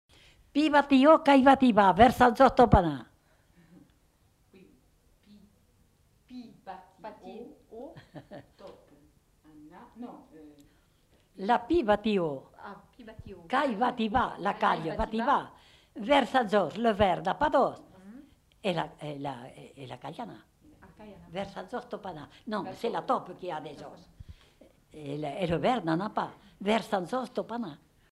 Aire culturelle : Haut-Agenais
Lieu : Condezaygues
Genre : forme brève
Effectif : 1
Type de voix : voix de femme
Production du son : récité
Classification : virelangue